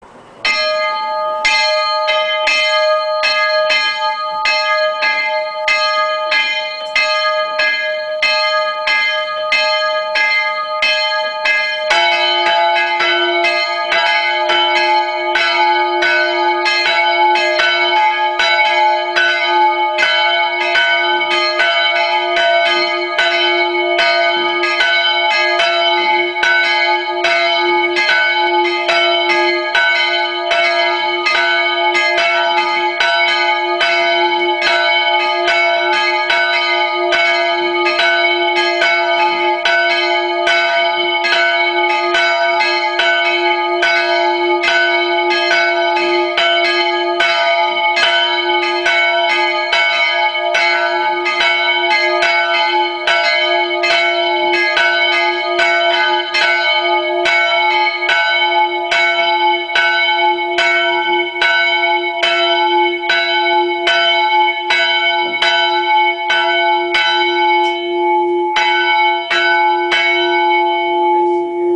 Audio- und Video-Beispiele des Geläutes (Im Turm drin [= Aufnahmeort] stört der Autobahnlärm nicht, dafür hat man ein in grösserer Entfernung nicht mehr hörbares "Summen mit Schwebung" im Ton.) St. Georgs-Glocken (mp3 Audio-Datei)
Glockenprobe.mp3